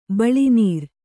♪ baḷi nīr